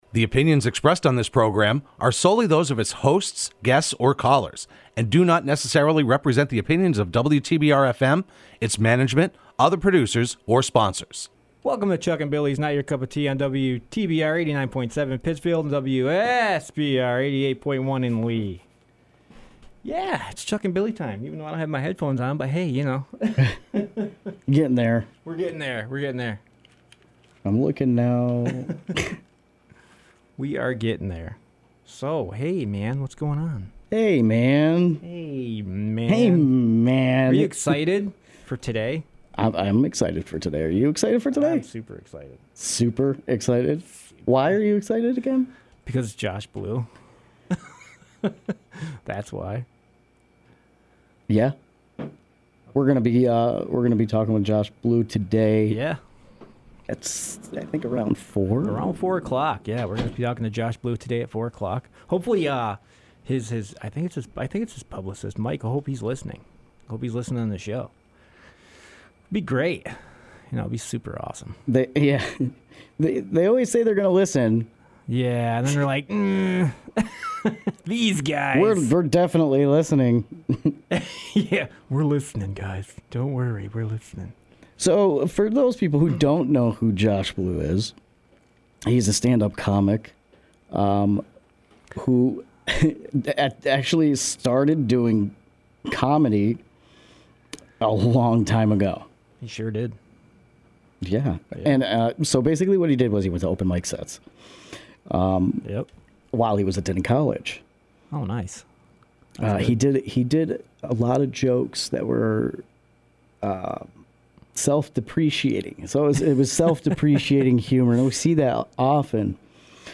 Broadcast live every Wednesday afternoon at 3:30pm. This week they welcome comedian Josh Blue to the program.